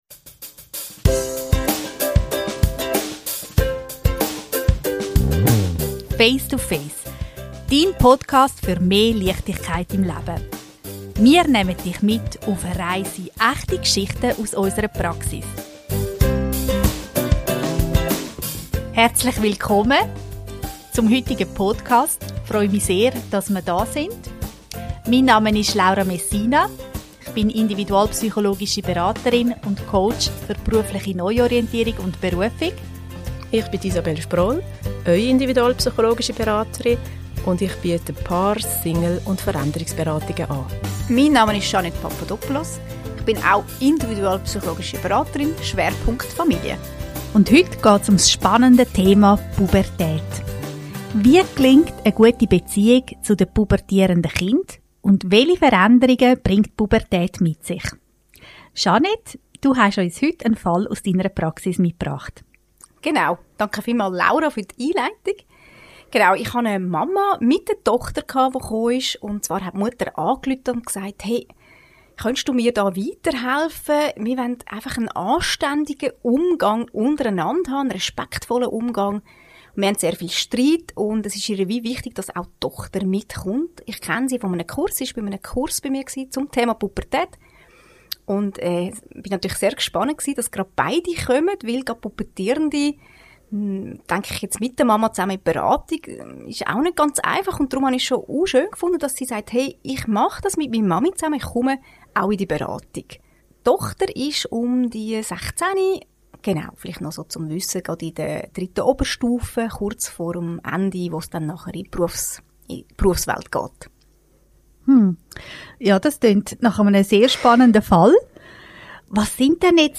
In dieser Folge sprechen die drei individualpsychologischen Beraterinnen